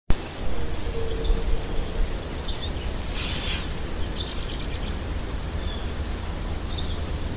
Mottle-cheeked Tyrannulet (Phylloscartes ventralis)
Class: Aves
Location or protected area: Reserva Natural del Pilar
Condition: Wild
Certainty: Recorded vocal